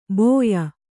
♪ bōya